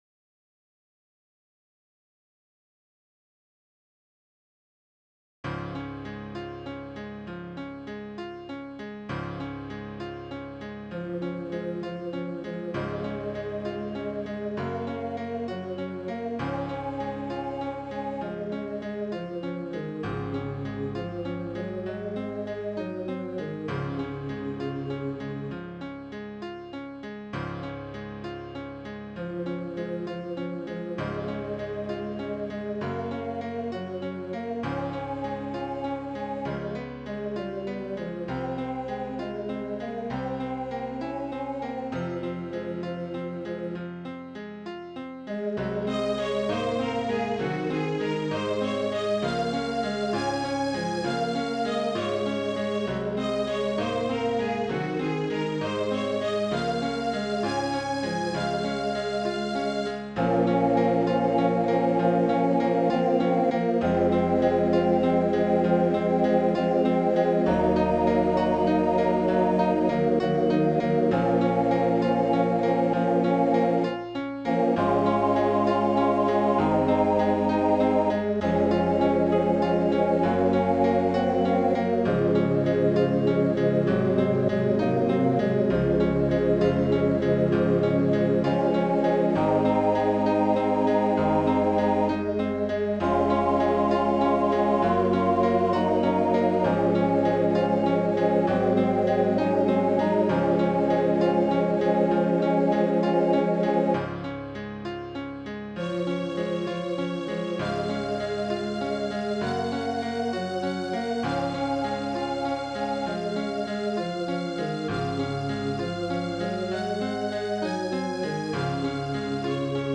O Holy Night, TTB with violin obbligato (by Bonnie Heidenreich -- TTB)
Voicing/Instrumentation: TTB We also have other 25 arrangements of " O Holy Night ".
Violin Accompaniment